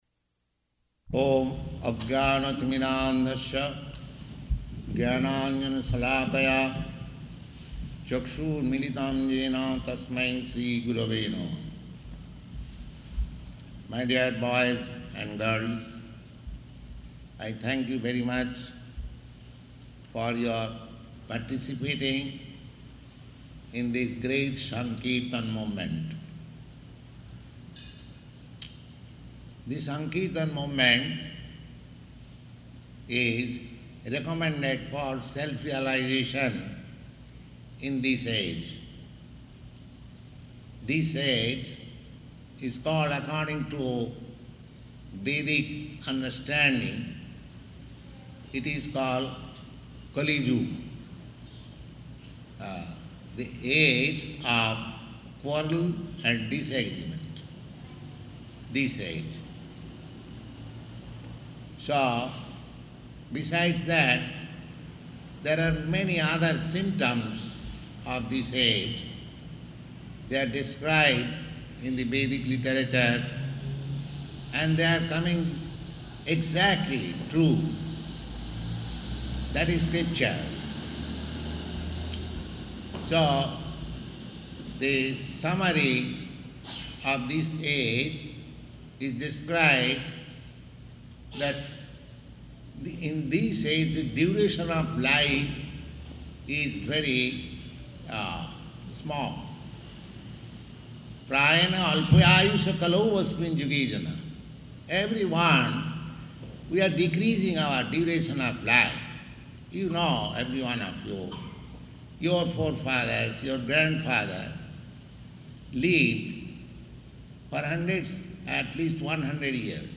Type: Lectures and Addresses